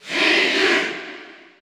Category: Crowd cheers (SSBU) You cannot overwrite this file.
Ryu_Cheer_French_PAL_SSBU.ogg